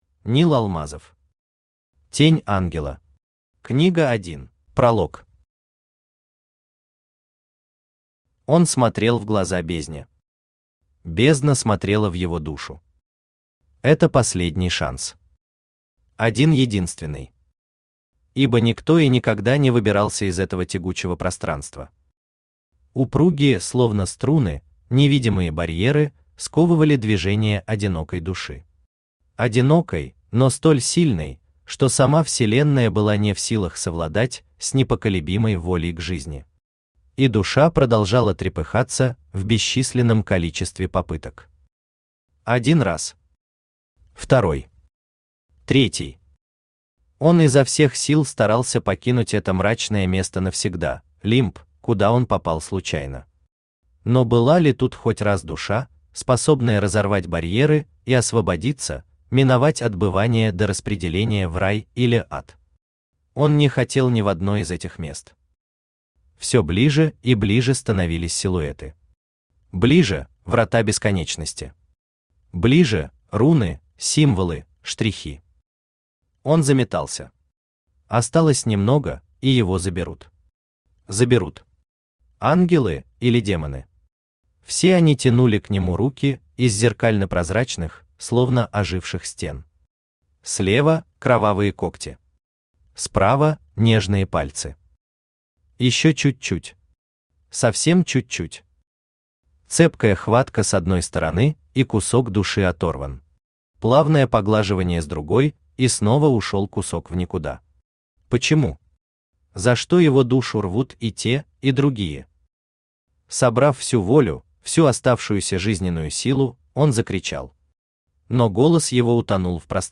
Аудиокнига Тень ангела. Книга 1 | Библиотека аудиокниг
Книга 1 Автор Нил Алмазов Читает аудиокнигу Авточтец ЛитРес.